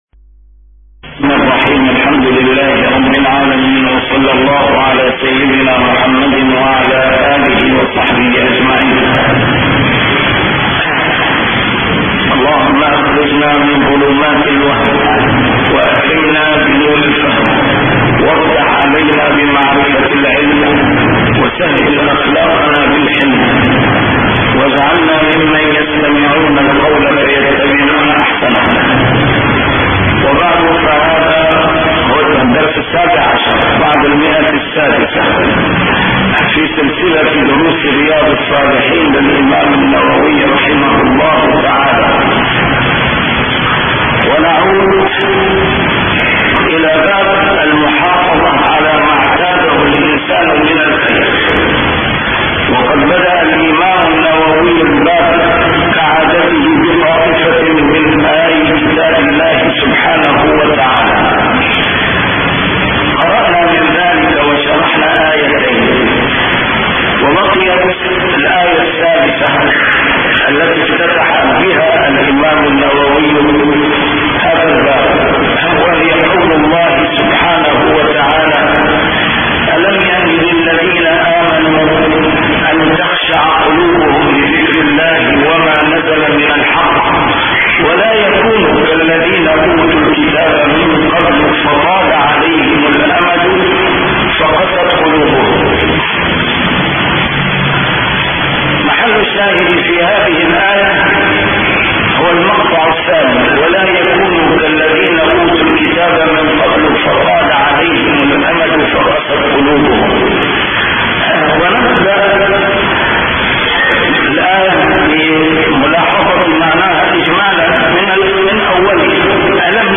A MARTYR SCHOLAR: IMAM MUHAMMAD SAEED RAMADAN AL-BOUTI - الدروس العلمية - شرح كتاب رياض الصالحين - 617- شرح رياض الصالحين: المحافظة على ما اعتاده من الخير